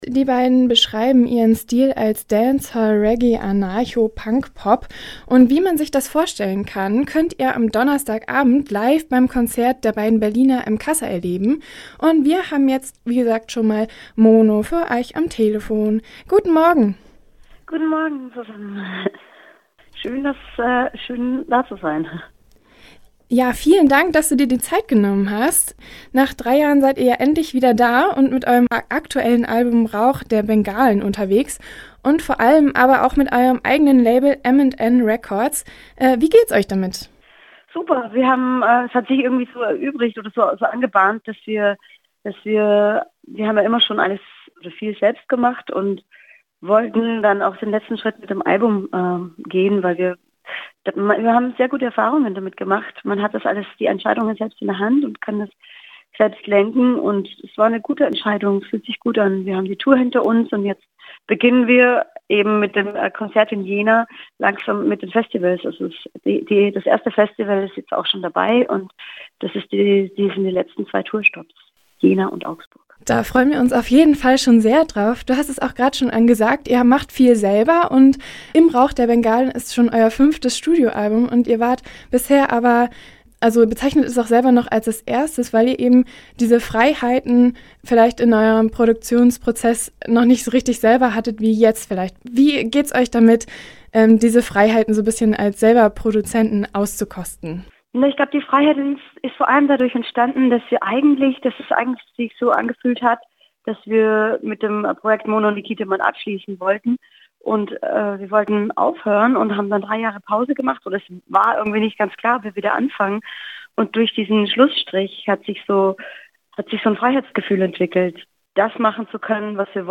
Im Interview: Mono & Nikitaman - Campusradio Jena
Mono & Nikitaman machen tanzbare Musik aus den verschiedensten Musikstilen. Mono war am Telefon und hat uns erzählt wie das neue Album entstanden ist und was ihnen an Konzerten am Besten gefällt.